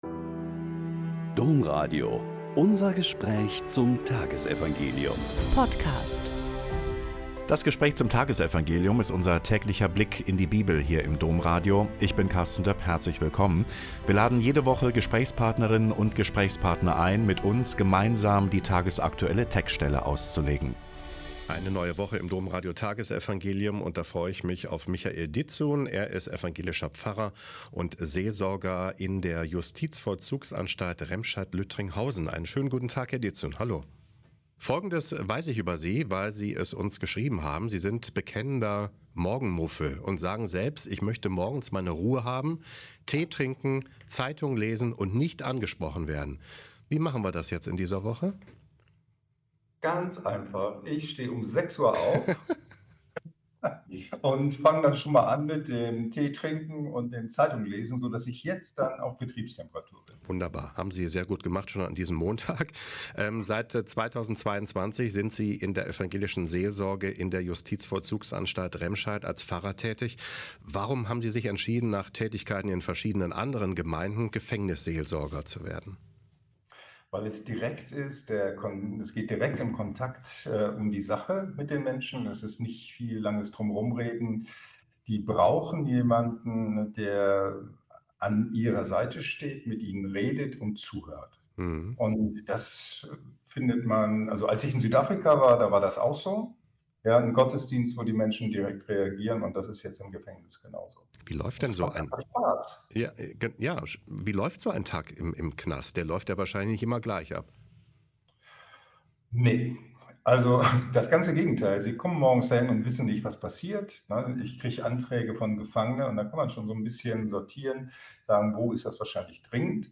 Lk 14,12-14 - Gespräch